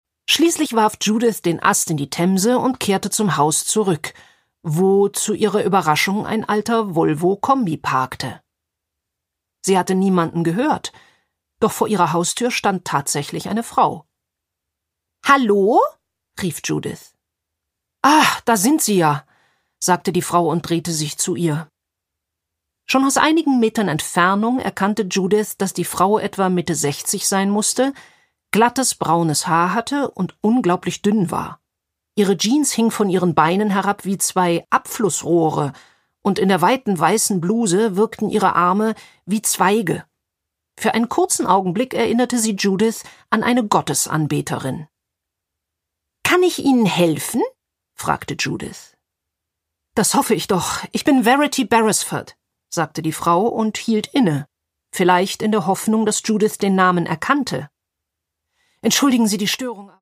Produkttyp: Hörbuch-Download
Gelesen von: Christine Prayon